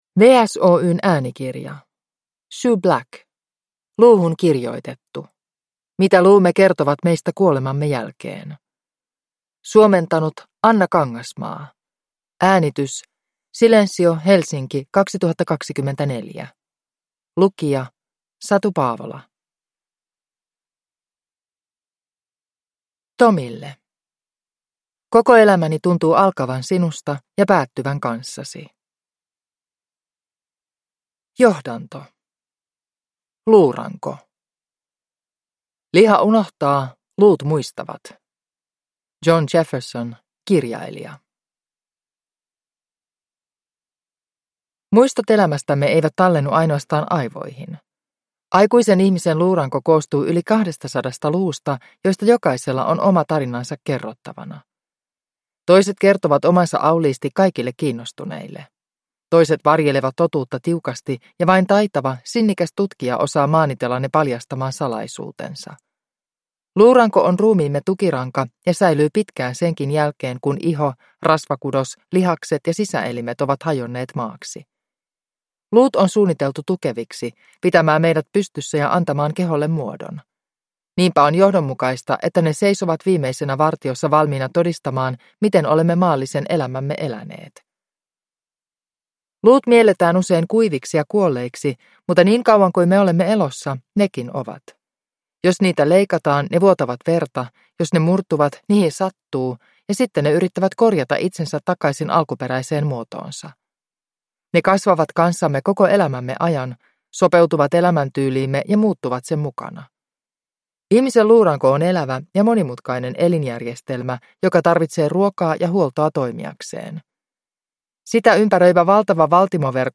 Luuhun kirjoitettu – Ljudbok